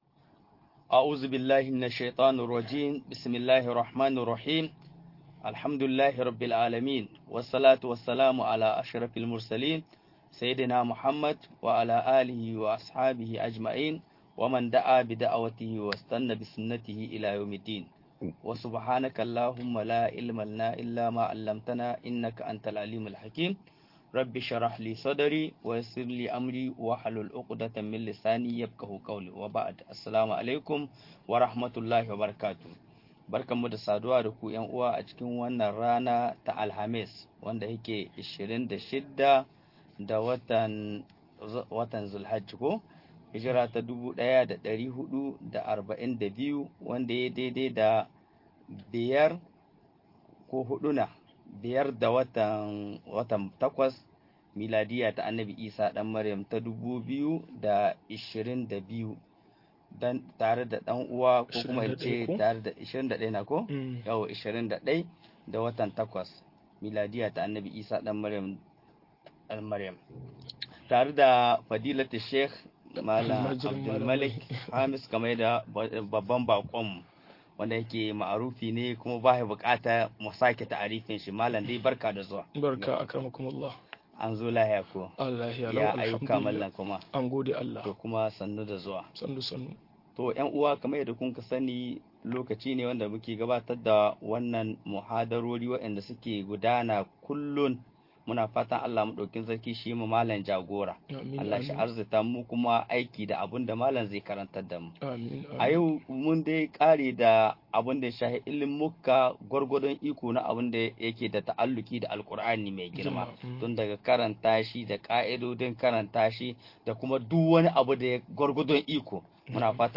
Ka'idodin ilimin hadissi - MUHADARA